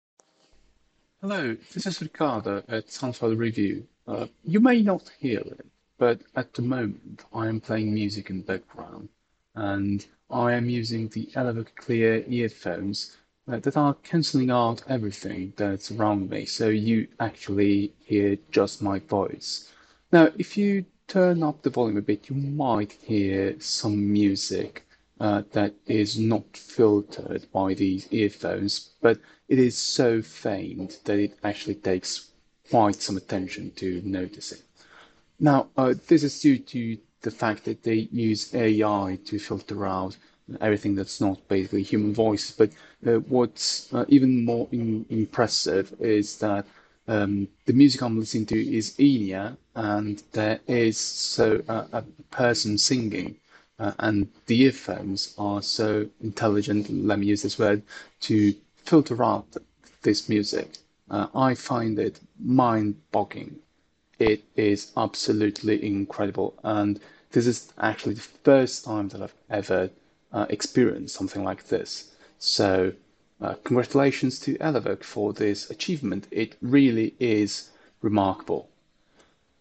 The Elevoc Clear uses three microphones per side to get to this result, combining their input to remove the unwanted noises; there’s also a so-called “voice accelerometer” which contributes to the removal of noise as well.
So, while I was recording this I was listening to music through my iLoud Micro Monitor speakers, which you probably won’t hear unless you turn up the volume and really pay attention to it. You may also hear a mistake I’ve made as well. Elevoc’s promise of its AI technology to remove background noise is not empty and, in fact, it is kept to its fullest.